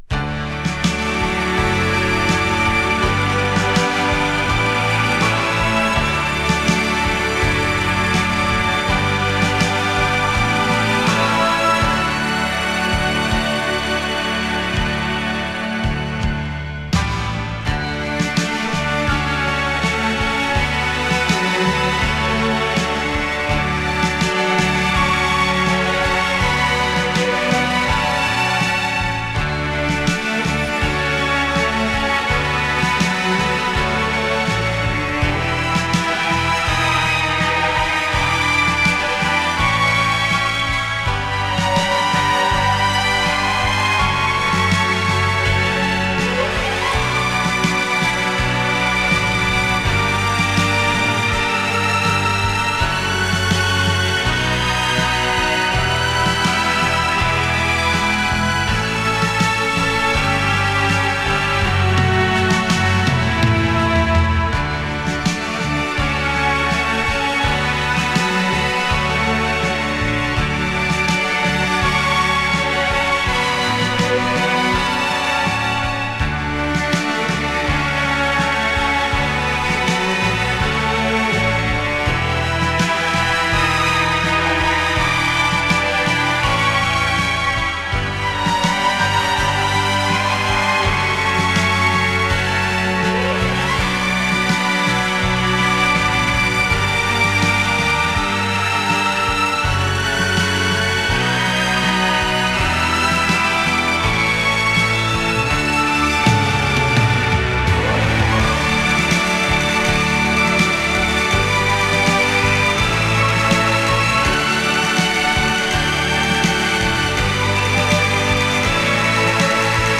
ストリングスが前面に出たイージー・リスニング要素たっぷりのインスト・アルバム。